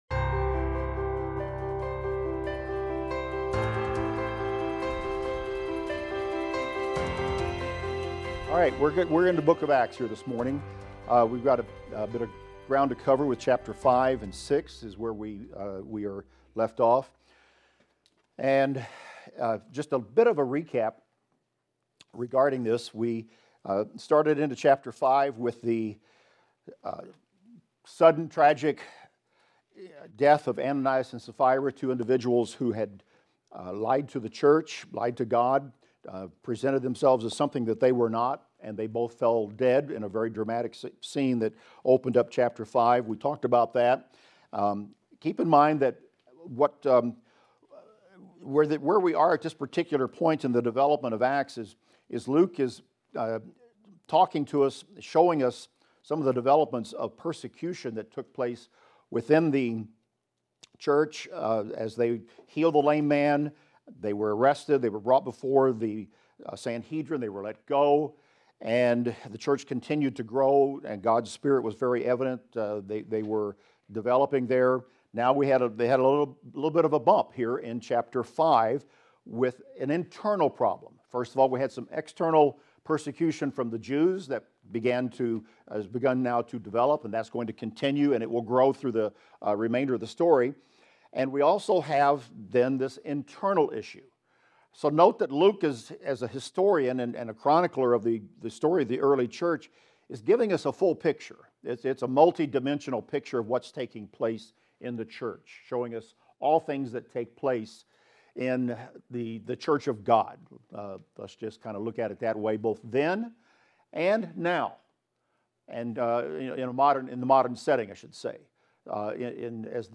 In this class we will discuss Acts 5:26-41 thru Acts 6:1-7 and notice the following events: the apostles being persecuted by the Sanhedrin for teaching in Christ's name; how a Pharisee named Gamaliel spoke up on behalf of the apostles; and the choosing of seven men to help the disciples distribute food.